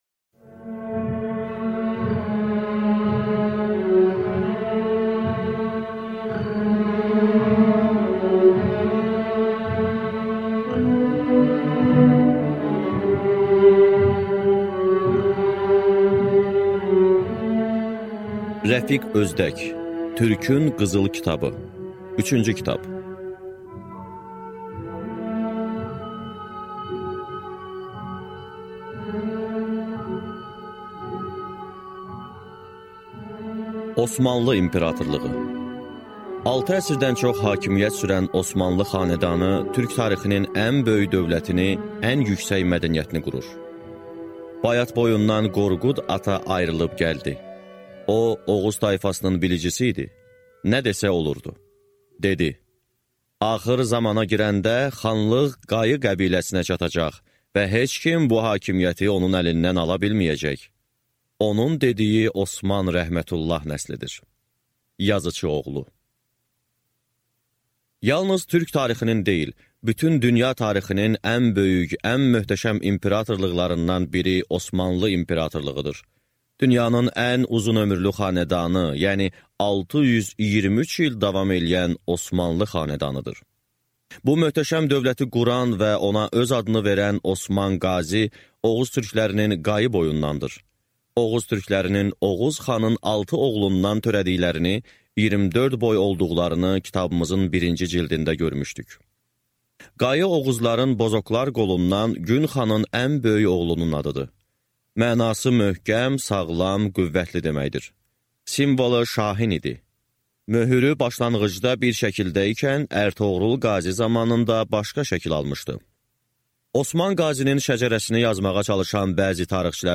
Аудиокнига Türkün qızıl kitabı 3-cü kitab | Библиотека аудиокниг